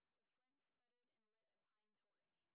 sp29_street_snr30.wav